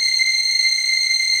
DM PAD3-04.wav